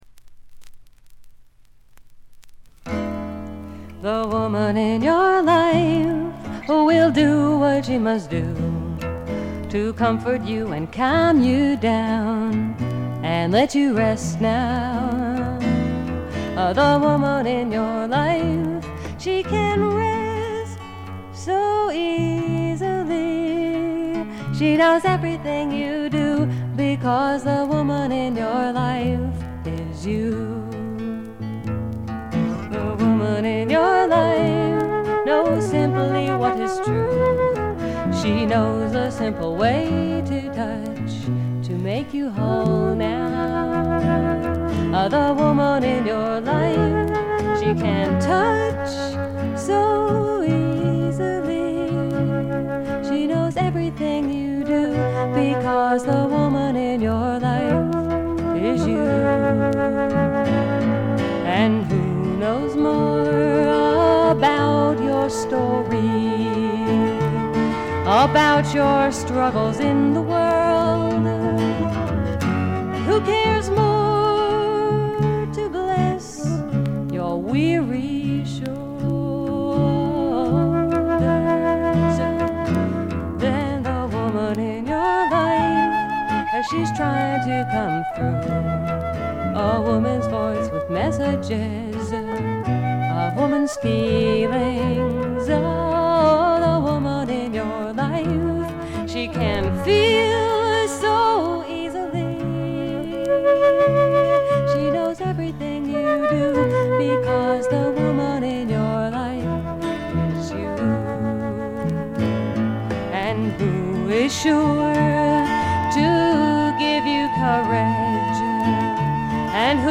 細かなバックグラウンドノイズやチリプチは多め大きめに出ますが鑑賞を妨げるほどではないと思います。
自主フォーク、サイケ・フォーク界隈でも評価の高い傑作です。
試聴曲は現品からの取り込み音源です。